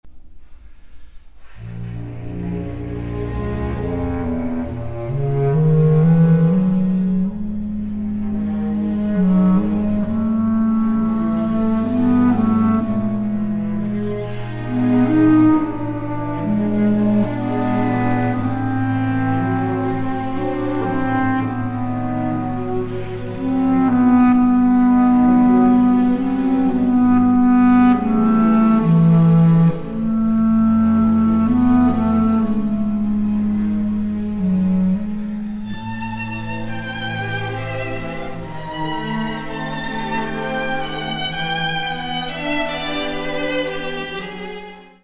violin
Concerto for Violin and Bass Clarinet
Misterioso (10:50)